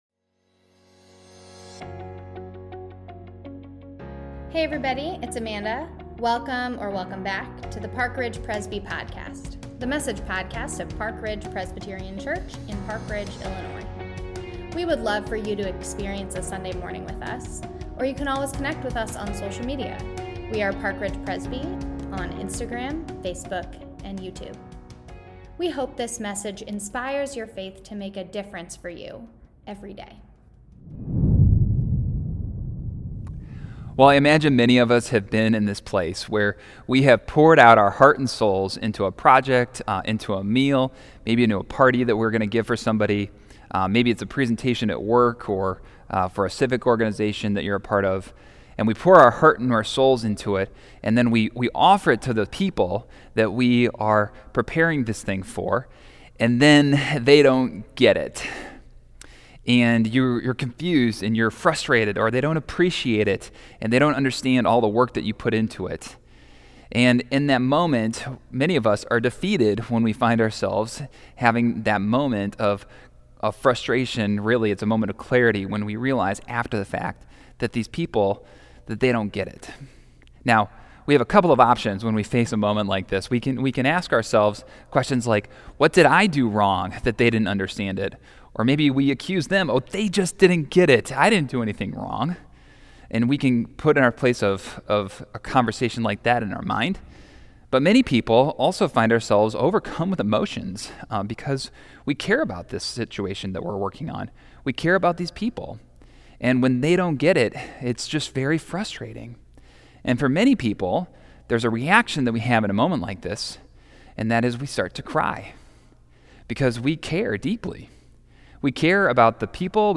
Sermon-Only.mp3